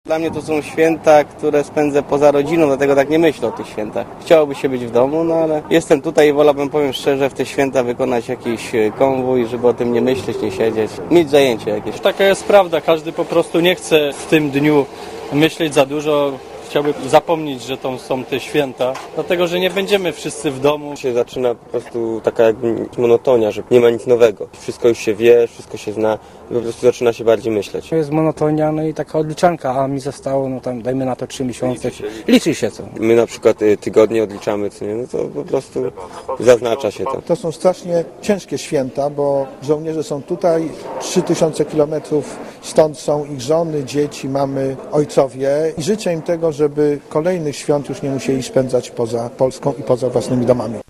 Relacja z Iraku